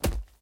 headshot.ogg